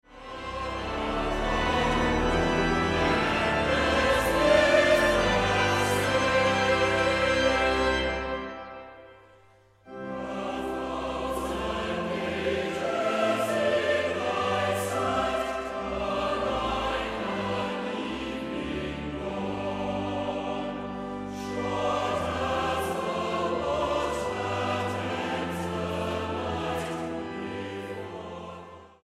The Scottish Festival Singers - Favourite Hymns Vol 5
STYLE: Hymnody
This is a very well recorded album that shows the choir at its best, musically produced with a high recording quality.